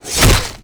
VEC3 FX Reverse 45.wav